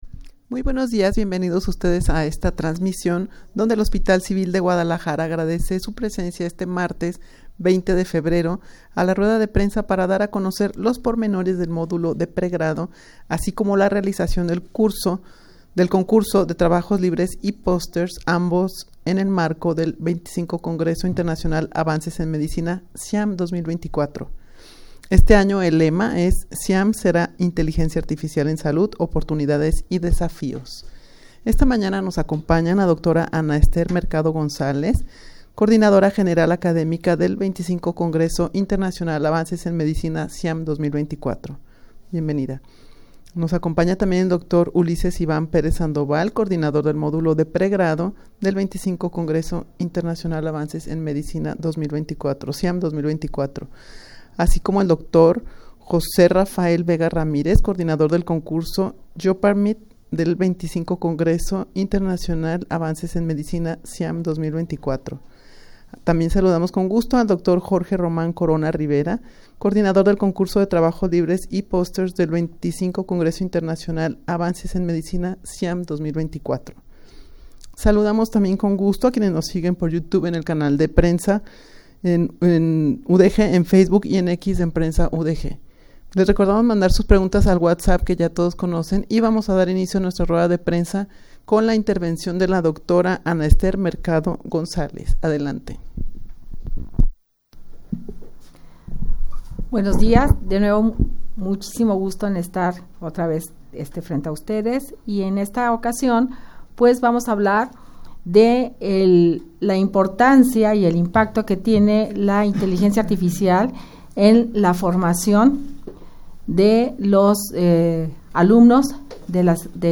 Audio de la Ruda de Prensa